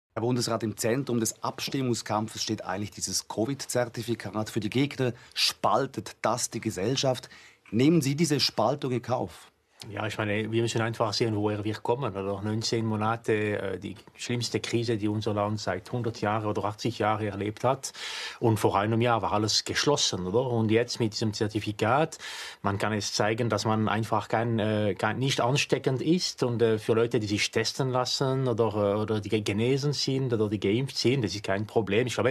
Das Zertifikat das man nur nach einer "Impfung" bekommt ist eine Garantie dafür das man nicht ansteckend ist. Er tätigte diese Falschaussage auch noch im Vorlauf einer eidgenössischen Abstimmung zum Covid19-Gesetz, was die Schwere dieser von SRF im Interview nicht korrigierten Fake-News nur noch erhöht.